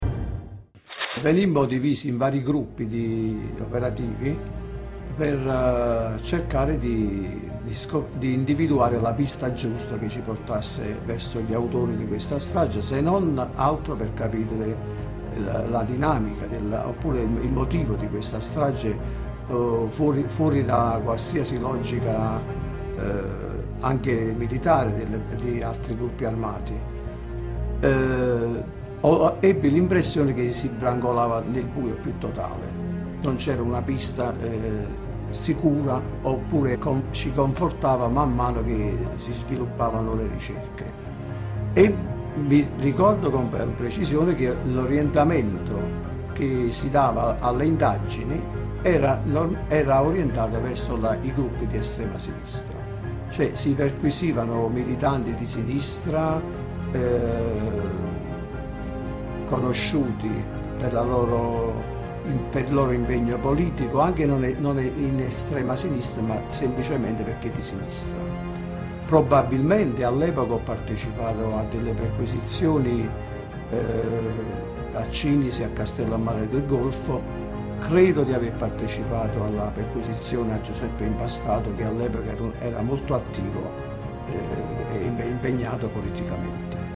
Quelle che ascolteremo di seguito sono le voci tratte da un documento filmato che è facilmente reperibile in rete.